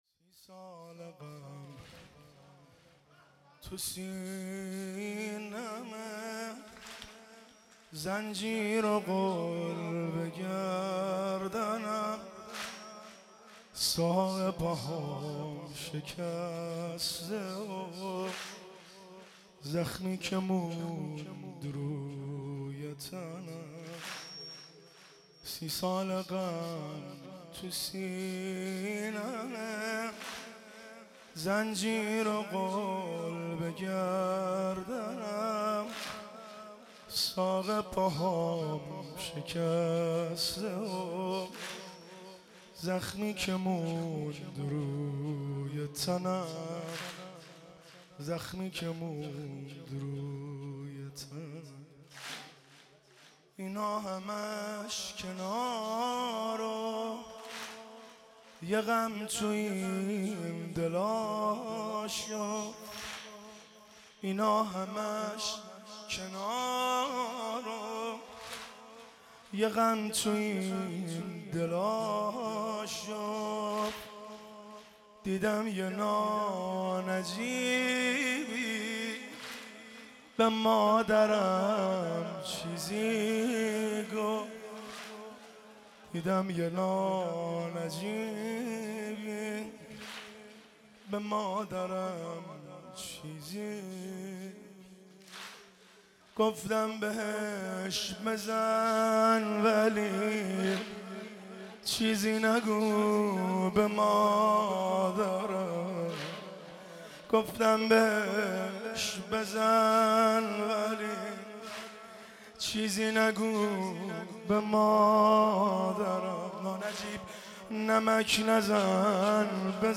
در هیات بین الحرمین تهران برگزار شد
مداحی